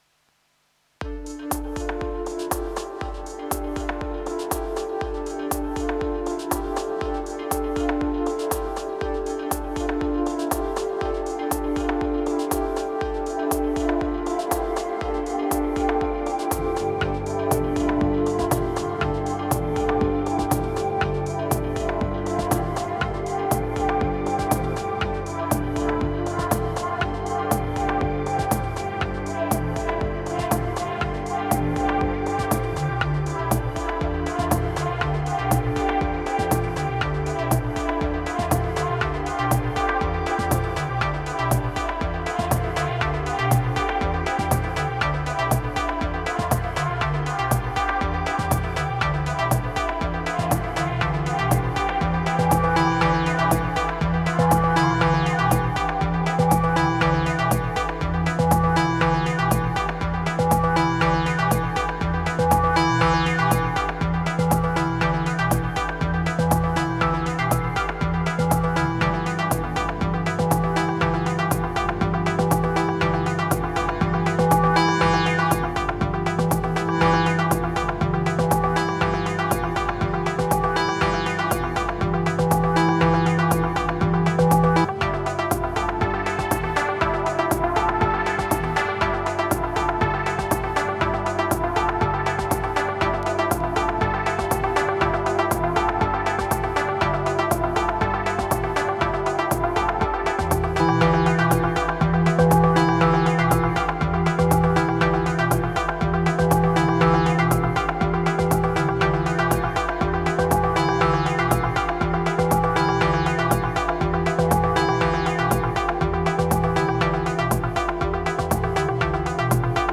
Bad moods.
96📈 - 90%🤔 - 120BPM🔊 - 2026-01-23📅 - 265🌟